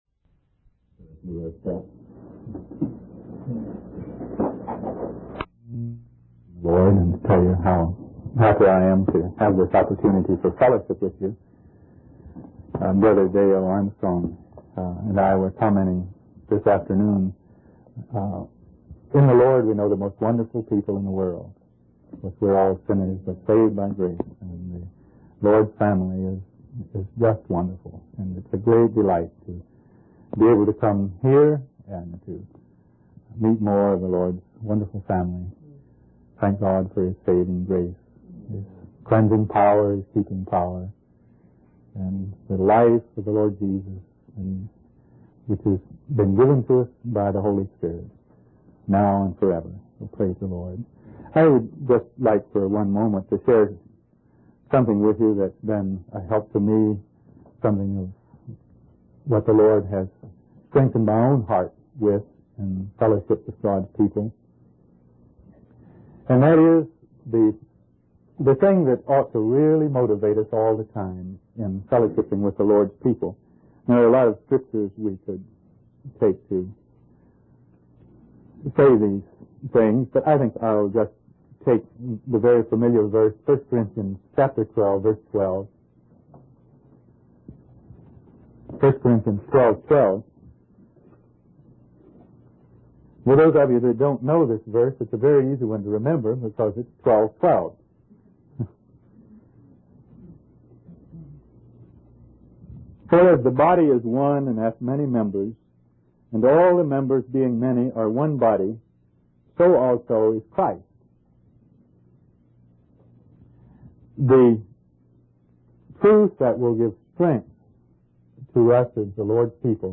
In this sermon, the speaker emphasizes the importance of understanding the three basics of the gospel of Jesus Christ: grace, faith, and the Holy Spirit.